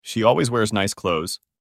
Portanto, a pronúncia será com som de /z/, ou seja, /klouz/.